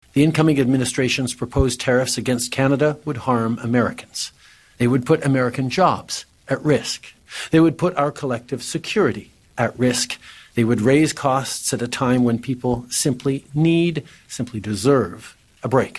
At a press conference, the minister and Prime Ministers talked about Canada coming together with the looming threat of a 25 per cent tariff on all Canadian goods beginning on Jan. 20, inauguration day – with Trudeau saying Canada will be ready if that happens.